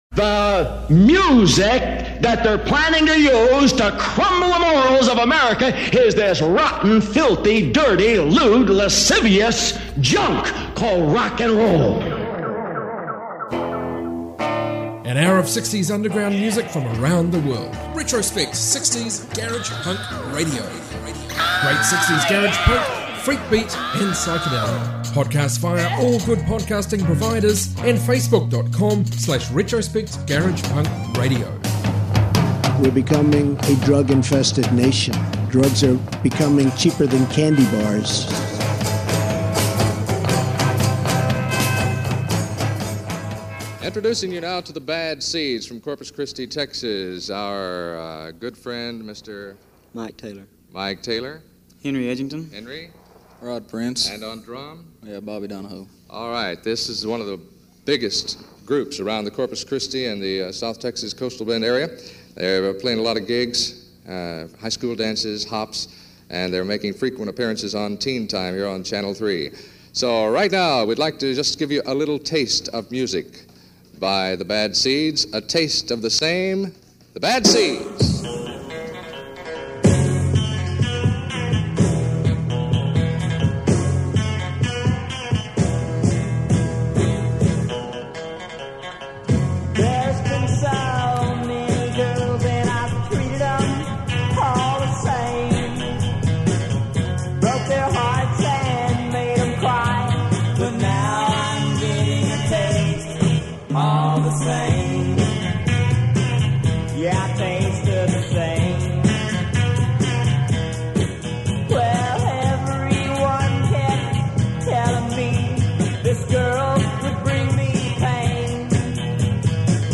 60s global garage music